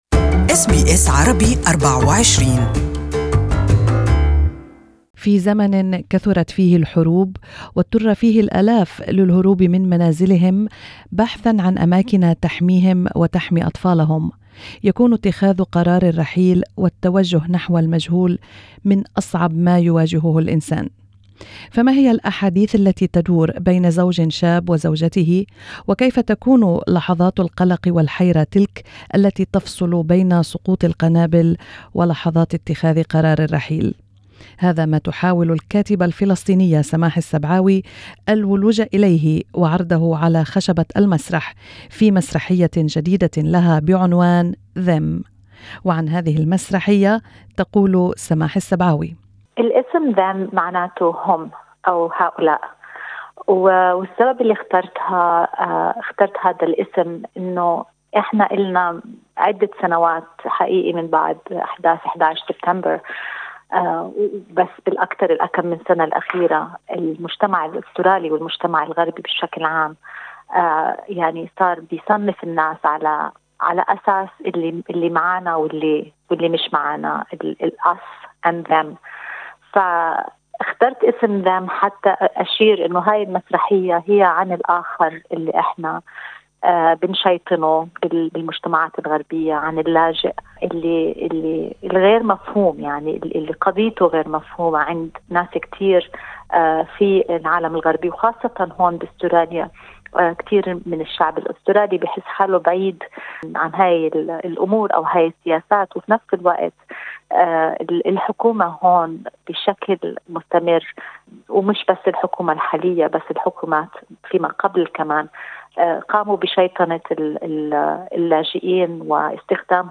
Listen to the interview in Arabic